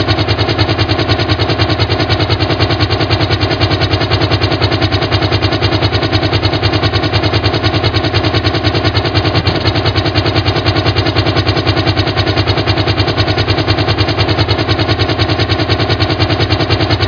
helicptr.mp3